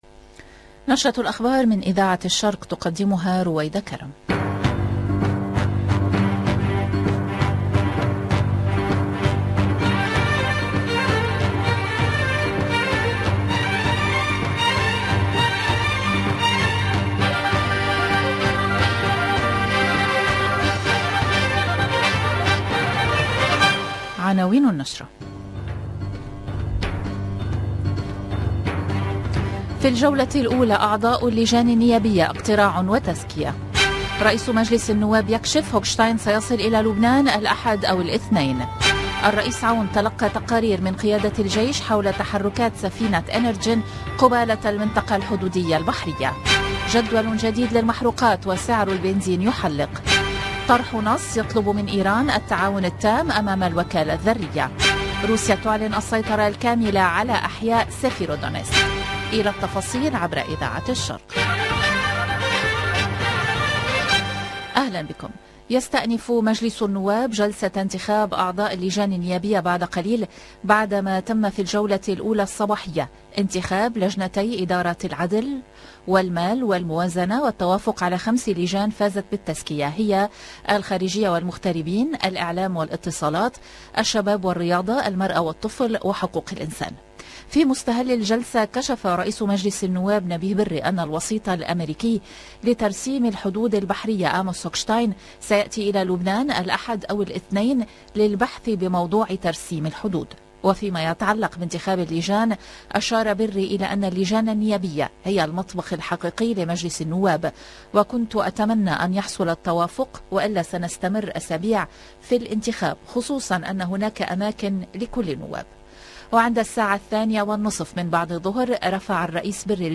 LE JOURNAL DU SOIR EN LANGUE ARABE DU 7/06/22